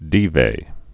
(dēvā)